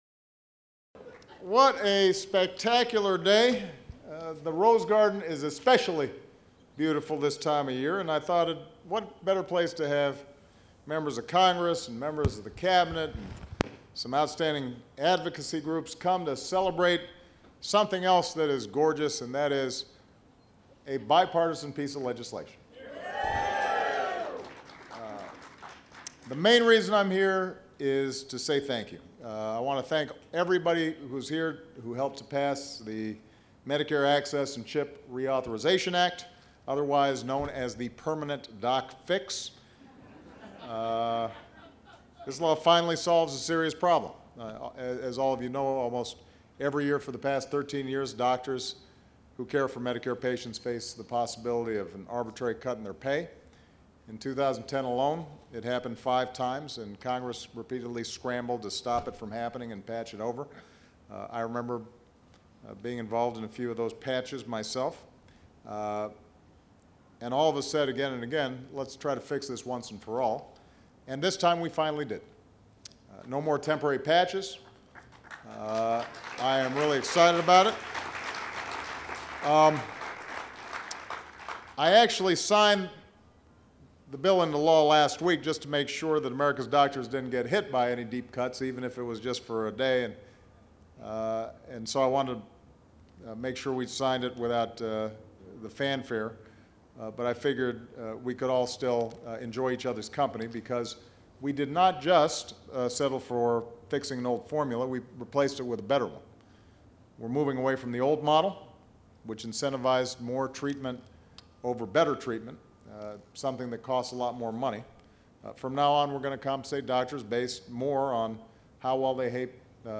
U.S. President Barack Obama speaks at a reception for supporters of the Medicare doc fix bill which permanently replaces the sustainable growth rate (SGR) formula used to determine Medicare payments for doctors
Obama praises the bipartisan cooperation that got the bill passed. Held at the White House.